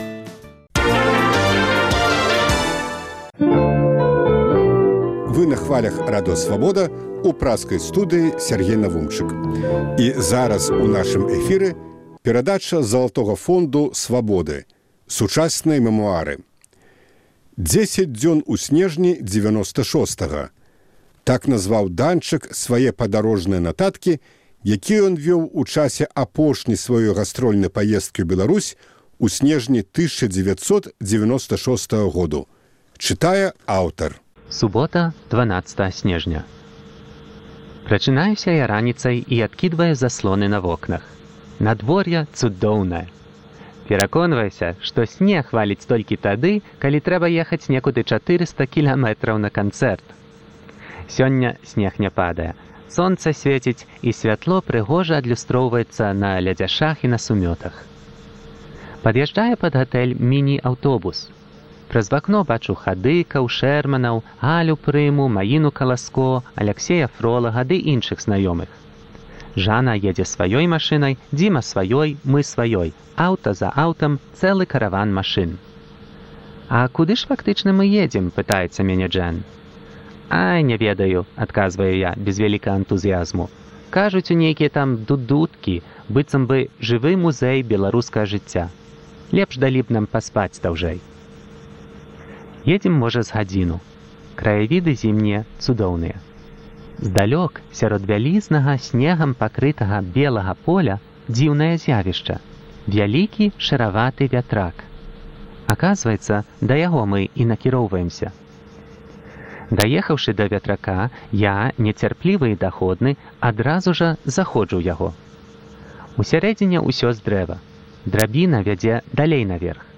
У чытальнай залі Свабоды - успаміны ў аўтарскім чытаньні.